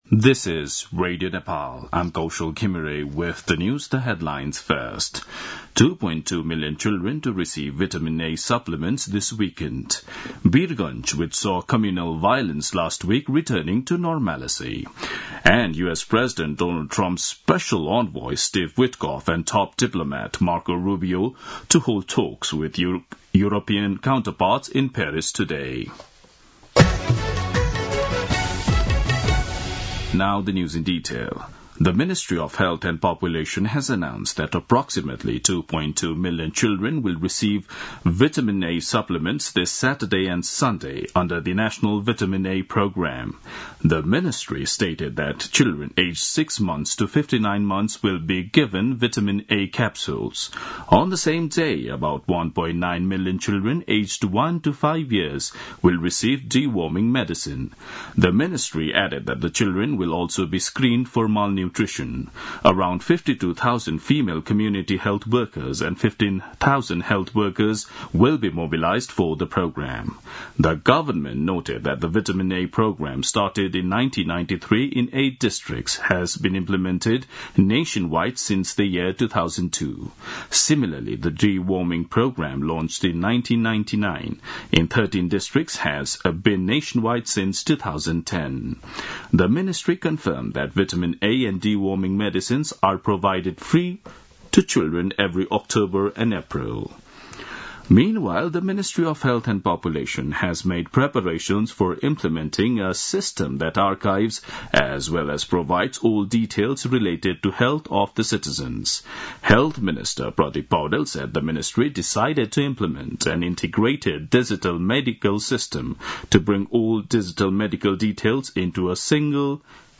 दिउँसो २ बजेको अङ्ग्रेजी समाचार : ४ वैशाख , २०८२